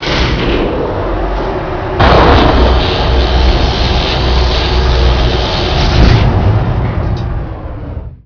gen_fire.wav